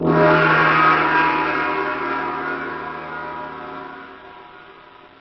gong.wav